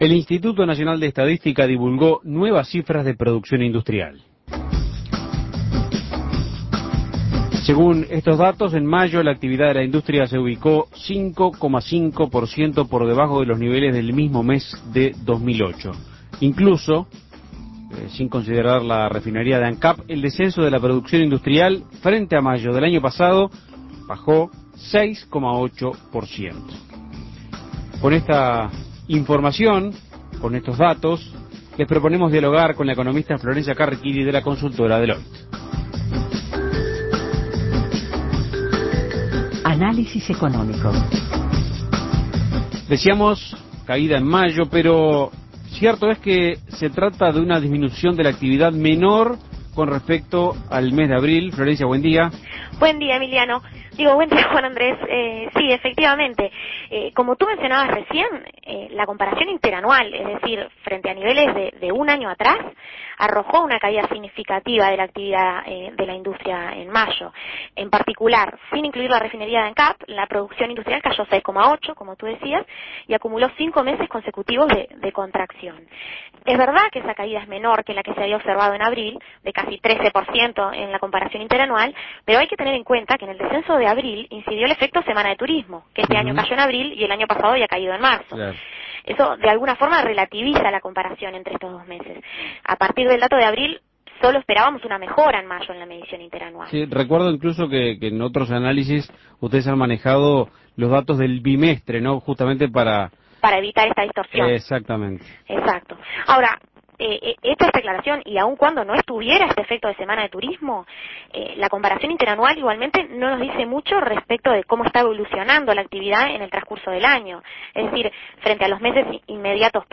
Análisis Económico ¿Qué mostraron los últimos datos de crecimiento industrial divulgados por el Instituto Nacional de Estadística?